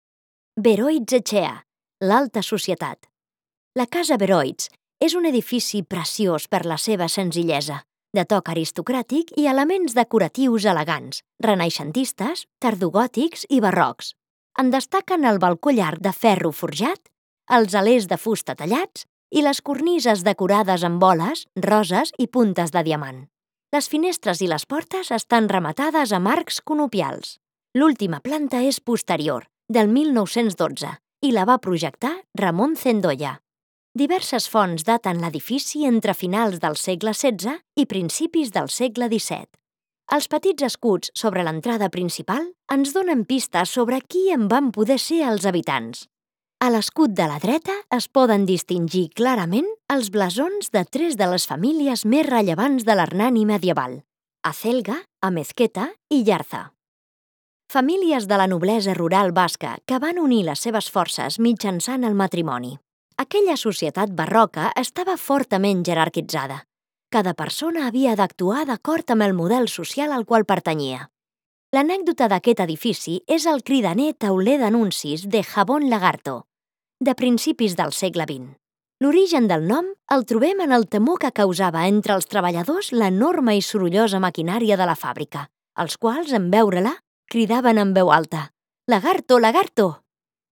Beroitz etxea – Hernani. Bisita guidatua